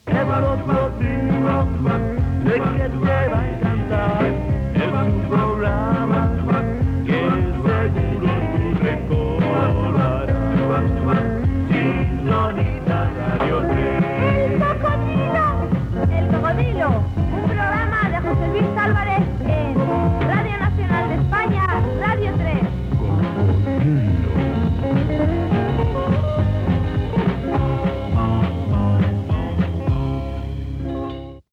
Careta musical del programa
Musical